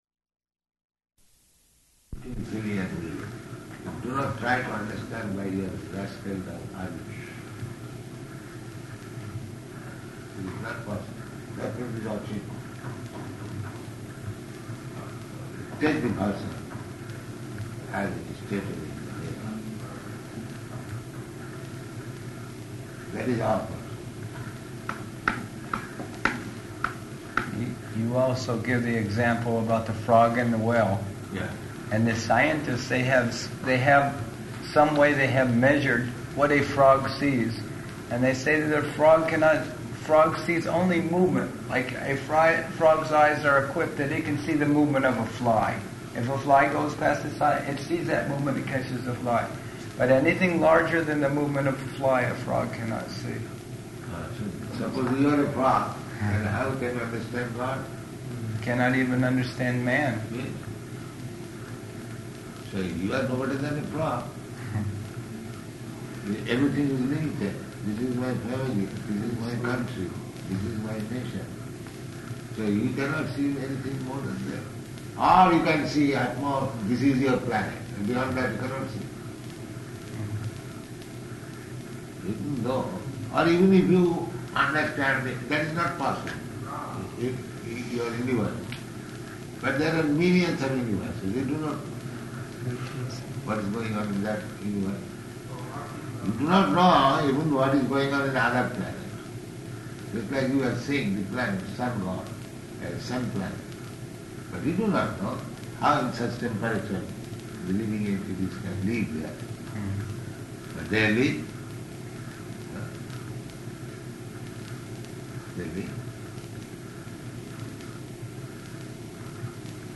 Room Conversation
Room Conversation --:-- --:-- Type: Conversation Dated: December 6th 1971 Location: Delhi Audio file: 711206R1-DELHI.mp3 Prabhupāda: ...